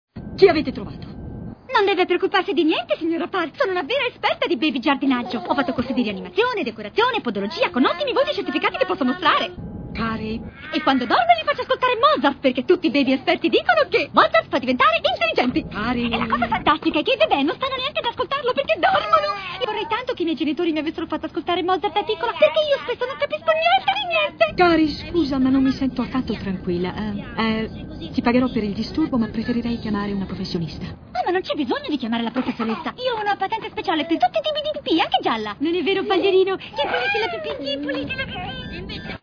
nel film d'animazione "Gli Incredibili", in cui doppia Kari.